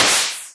hit.wav